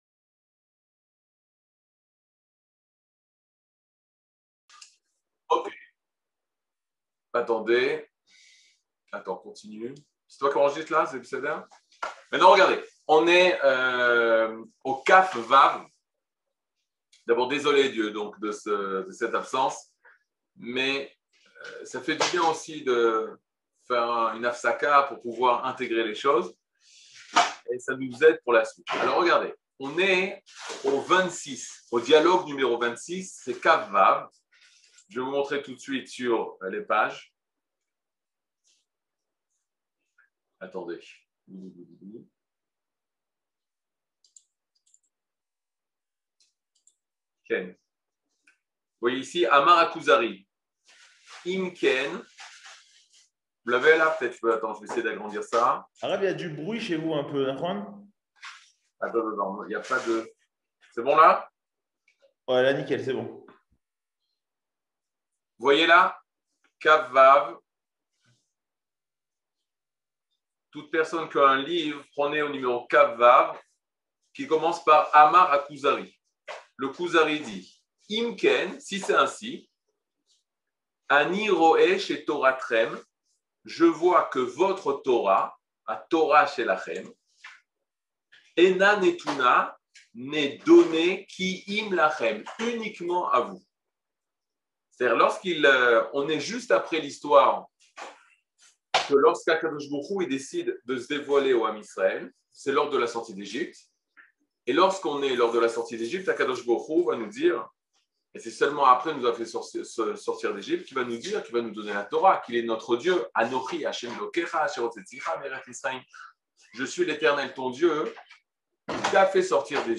Catégorie Le livre du Kuzari partie 23 00:59:49 Le livre du Kuzari partie 23 cours du 16 mai 2022 59MIN Télécharger AUDIO MP3 (54.75 Mo) Télécharger VIDEO MP4 (140.24 Mo) TAGS : Mini-cours Voir aussi ?